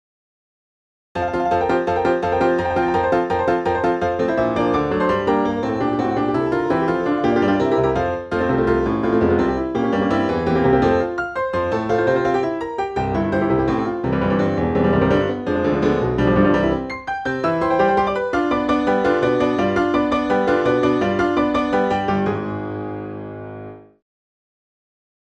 Another (seriously!) fast indication is to be found in the finale of the 8th symphony: